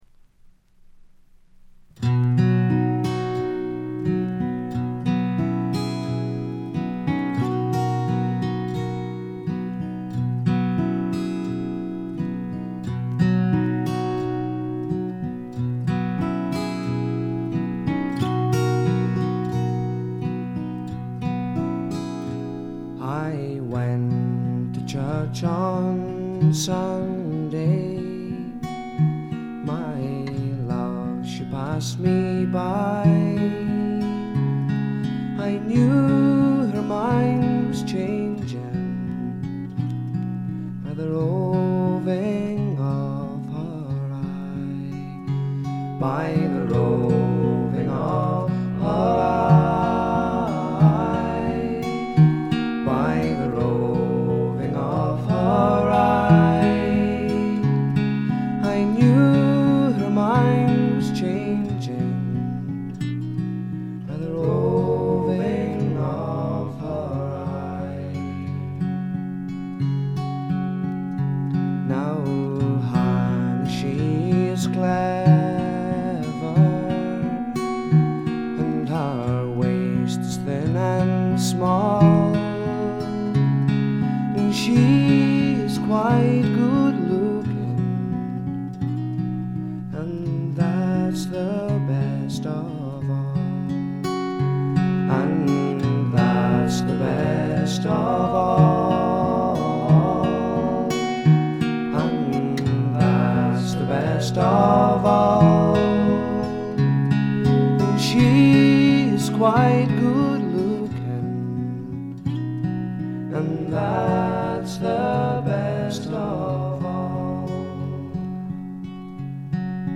ほとんどノイズ感無し。
ギター、フィドル、バックヴォーカル等すべてダギー自身によるもの。彼の特徴である噛みしめるように紡ぎ出すあたたかな歌声を心ゆくまで味わってください。
試聴曲は現品からの取り込み音源です。
Instruments [All], Vocals [All] - Dougie MacLean
Recorded And Mixed At Dambuster Studios, Essex